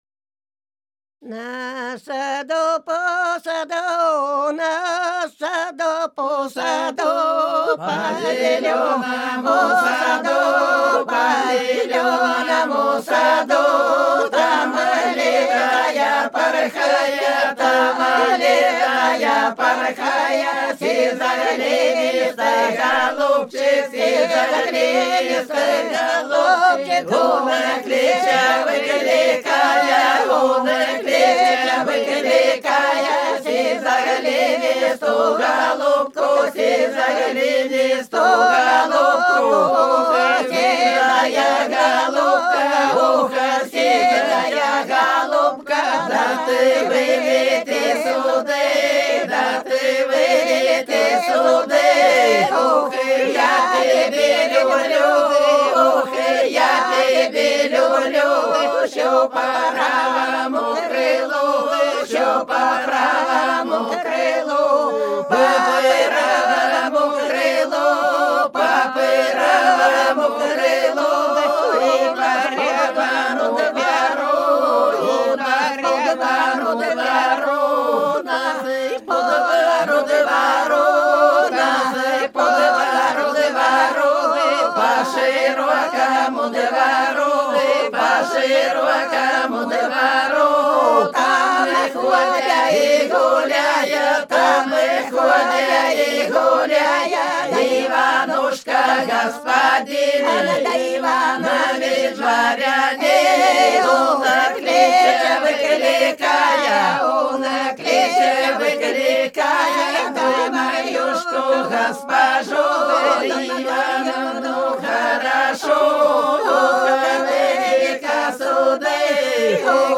Ансамбль села Хмелевого Белгородской области В нас по саду, по зеленому саду (величальная за столом на пропое)